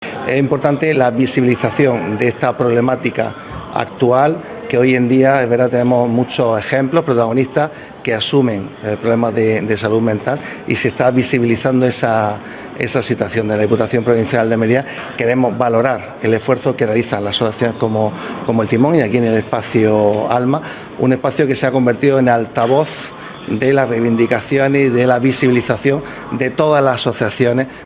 ANGEL-ESCOBAR-VICEPRESIDENTE-DIPUTACION-Y-DIPUTADO-BIENESTAR-SOCIAL.wav